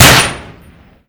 pm_shoot.ogg